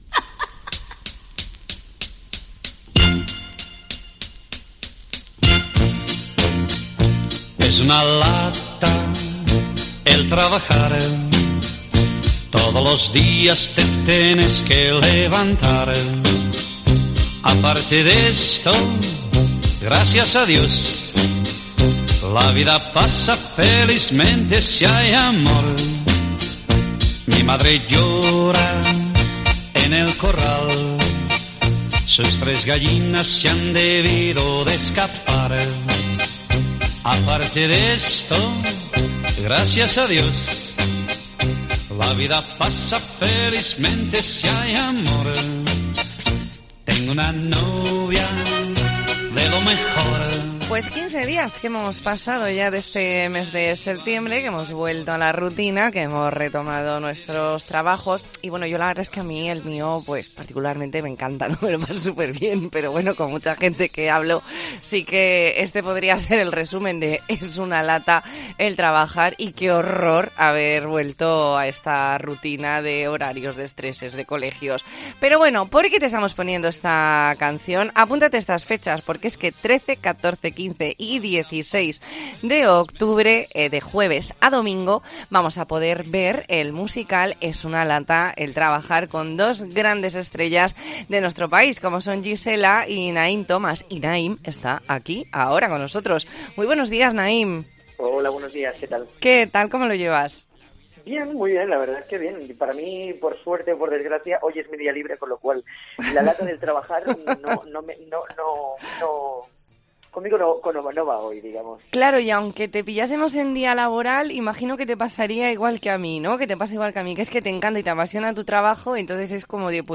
ntrevista en La Mañana en COPE Más Mallorca, jueves 15 de septiembre de 2022.